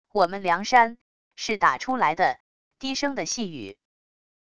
我们梁山……是打出来的……低声的细语wav音频